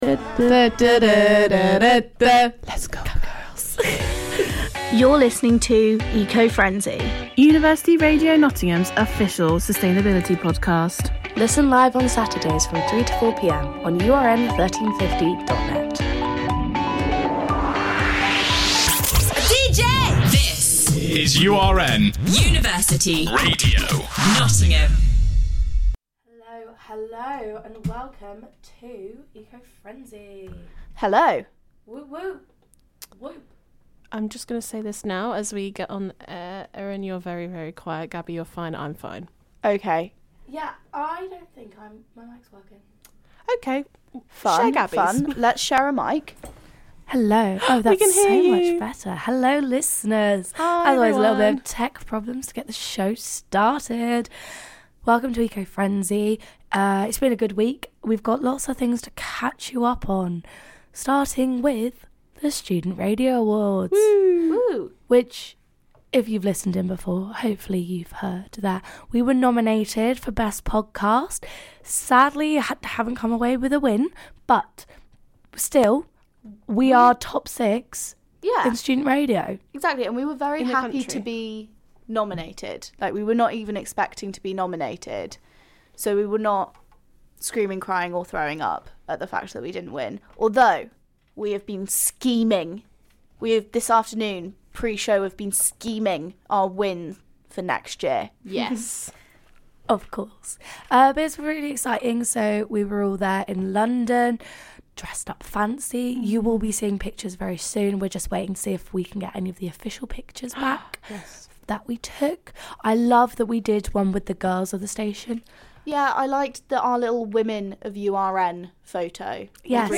The podcast this week starts off with a fun quiz about what can and can't be recycled. King Charles has been making headlines recently with a new ban on foie gras at royal residences and the hosts take a deeper dive into his environmentally friendly past. Last but certainly not least, as COP27 draws to a close they examine how the conference has gone. ~ Originally broadcast live on University Radio Nottingham on Saturday 19th November 2022 Share Facebook X Subscribe Next TTIAD: THE KIT RANKING SPECIAL!!!